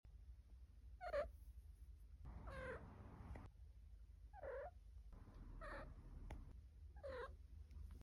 Activation Sound Sound Effects Free Download